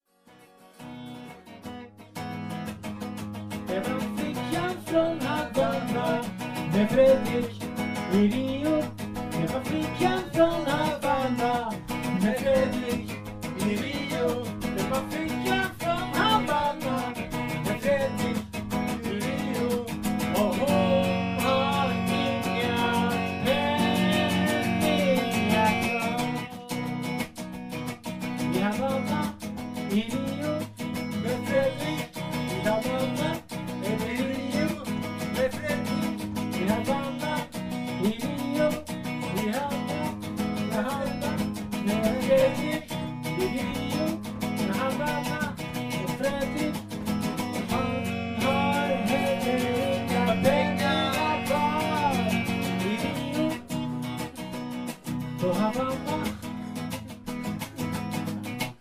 Ganska tidigt in på det nya året hade vi en tre timmars lång jam, där vi var väldigt inspirerade, och skapade mycket nytt.